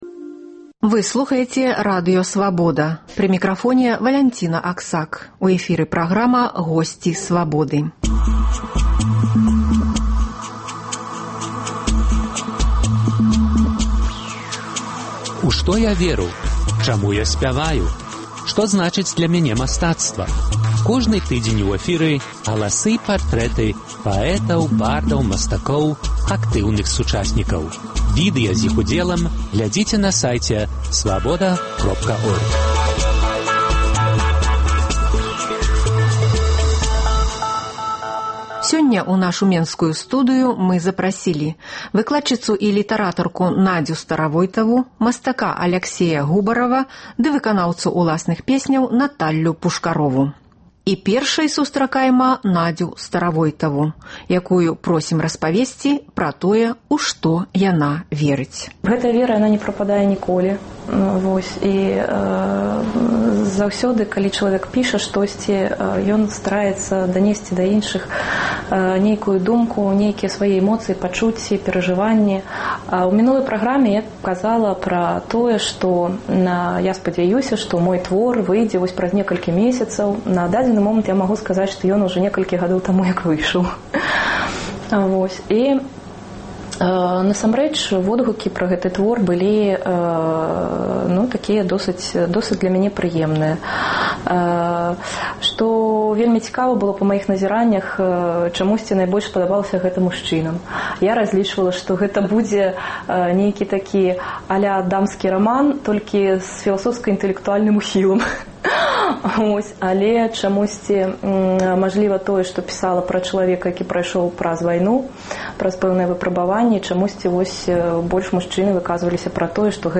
Сёньня ў нашай менскай студыі